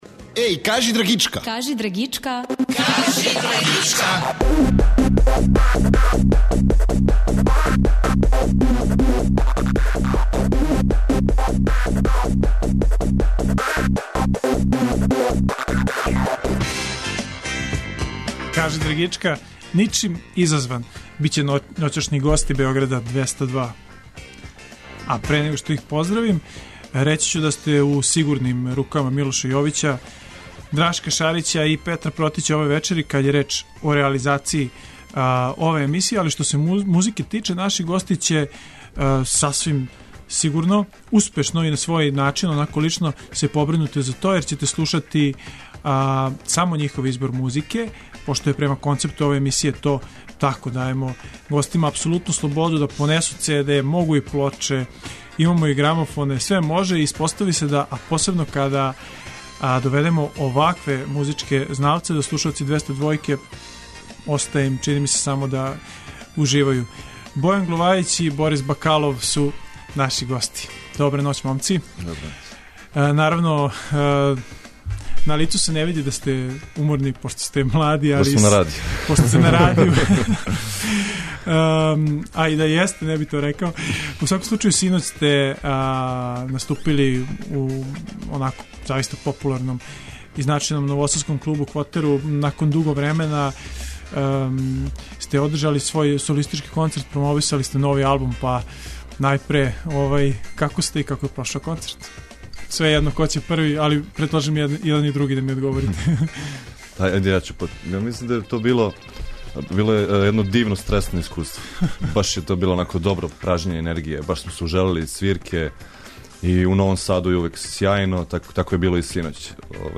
Како концепт емисије налаже, група ће вечерас емитовати песме по њиховом избору, које су донели у наш студио. Причаће о личним укусима кад је реч о музици, домаћој и страној сцени.